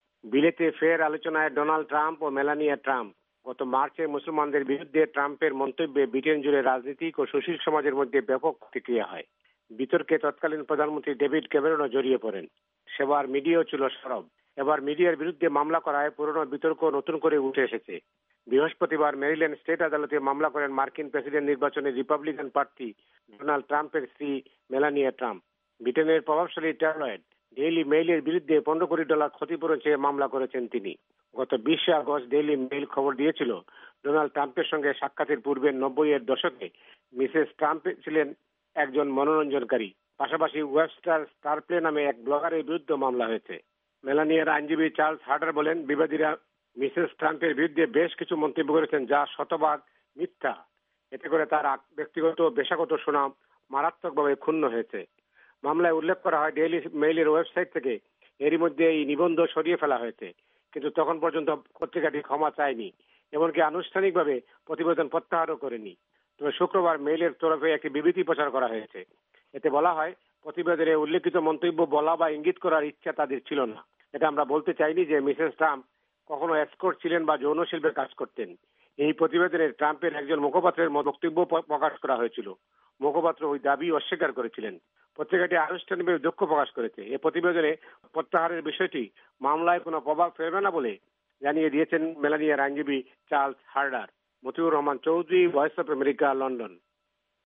রিপাবলিকান দলের প্রেসিডেন্ট প্রার্থী ডনাল্ড ট্রাম্প ও তার স্ত্রী মেলানিয়া ট্রাম্প আবারো আলোচনায়া এসেছেন বৃটেনে। লন্ডনের দৈনিক পত্রিকা ডেইলি মেইলের বিরুদ্ধে মেলানিয়ার মামলার প্রেক্ষিতে এবার এই আলোচনা। লন্ডন থেকে জানাচ্ছেন